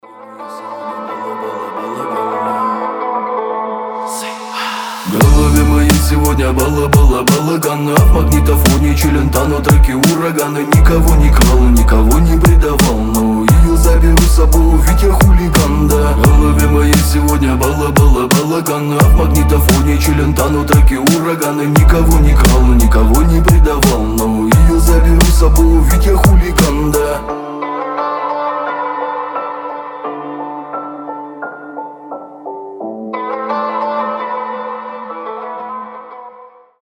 • Качество: 320, Stereo
пианино
восточные